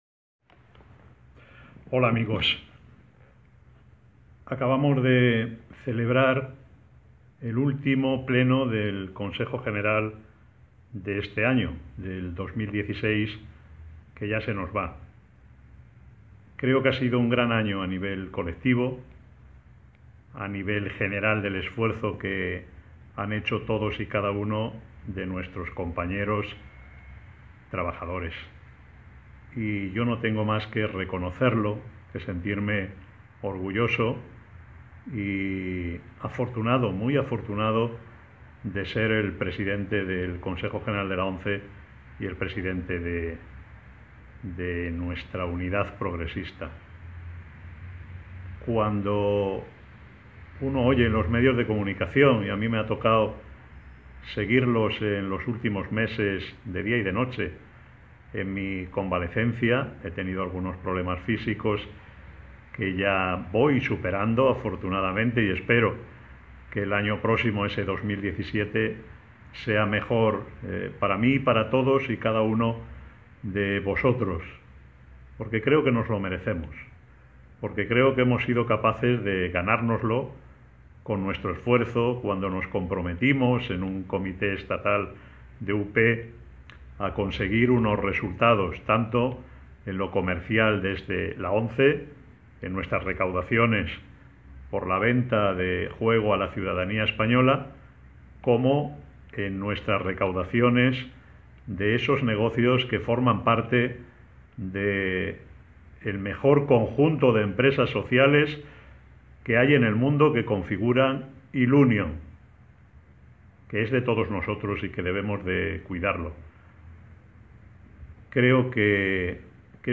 Miguel Carballeda, Presidente de UP, de la ONCE su Fundación e ILUNION, felicita las navidades a todos los asociados y simpatizantes de UP y hace un breve balance del año en este corte sonoro para Progreso Digital
Felicitación Navideña Miguel Carballeda Pte de UP.mp3